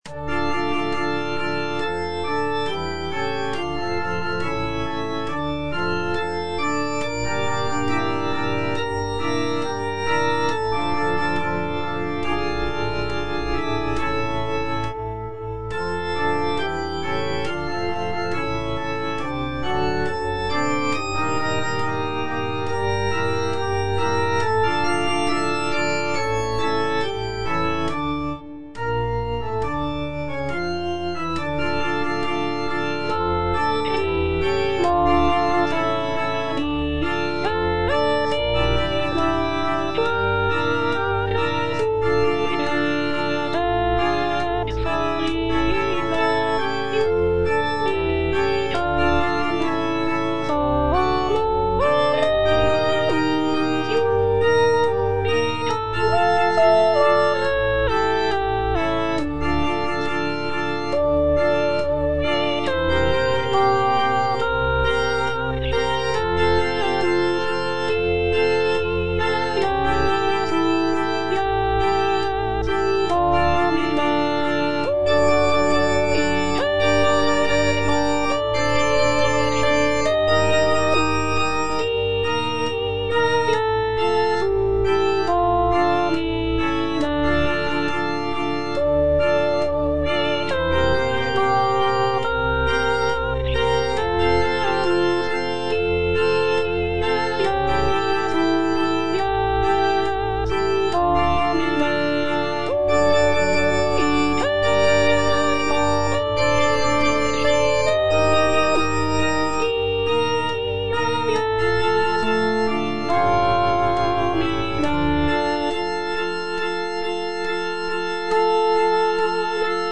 F. VON SUPPÈ - MISSA PRO DEFUNCTIS/REQUIEM Lacrimosa - Alto (Voice with metronome) Ads stop: auto-stop